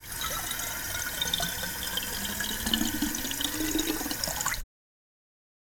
Fill glass bottle 01
Filling_glass_bottle_01.wav